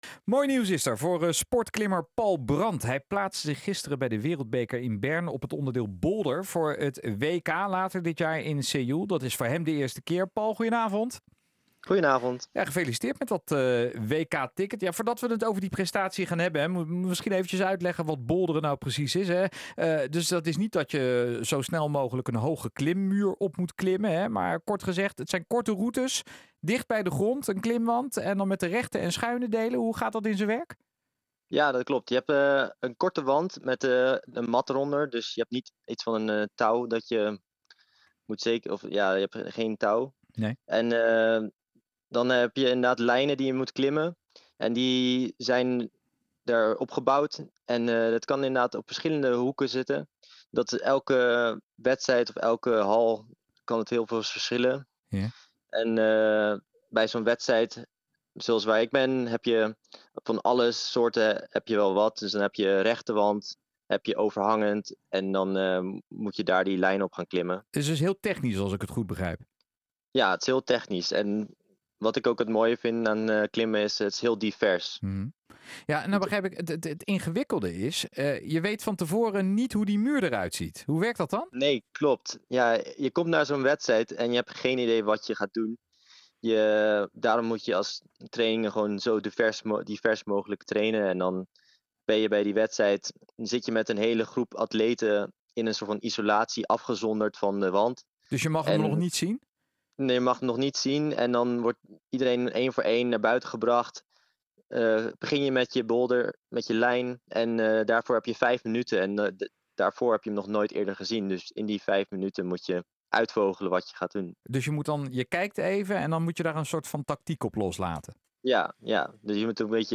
Op de landelijke radio